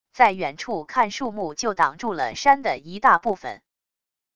在远处看树木就挡住了山的一大部分wav音频生成系统WAV Audio Player